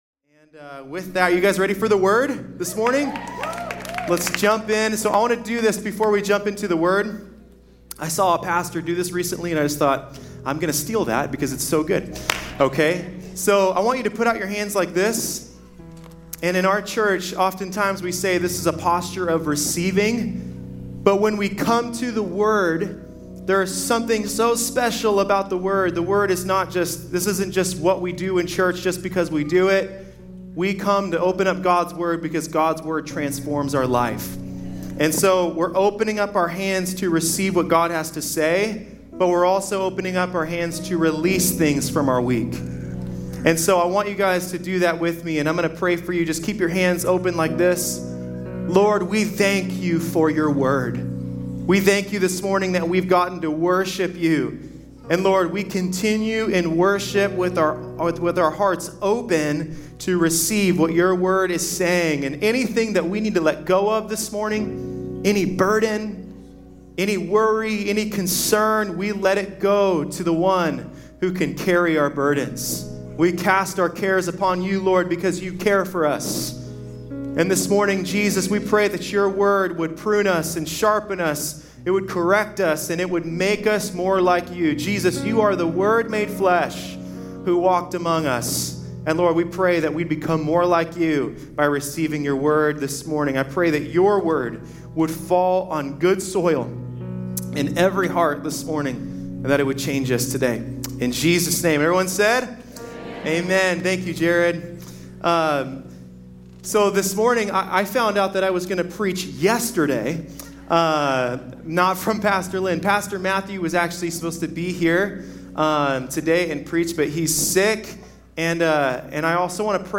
Welcome to the weekly audio Podcast from Valley Church in Caldwell, Idaho